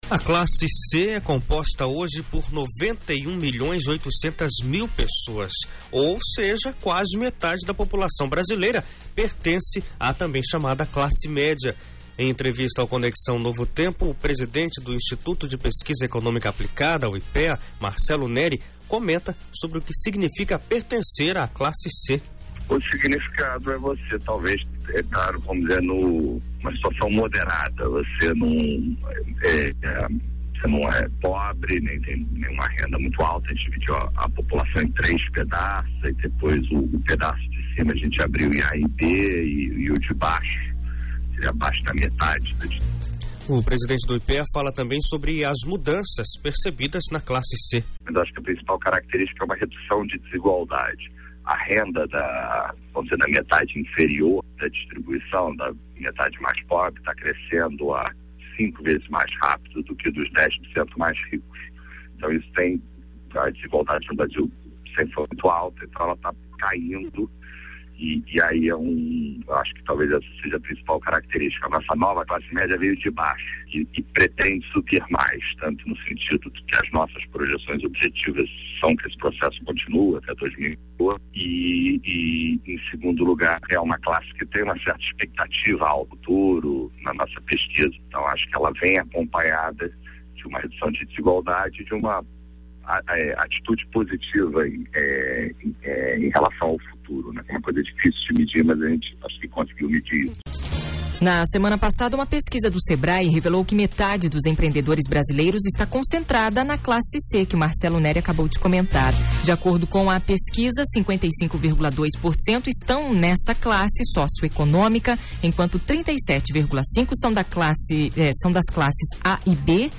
Em entrevista ao Conexão Novo Tempo o presidente do Instituto de Pesquisa Econômica Aplicada (IPEA), Marcelo Neri, comenta sobre o que significa pertencer a classe C. Na semana passada uma pesquisa do SEBRAE revelou que metade dos empreendedores brasileiros está concentrada na classe C.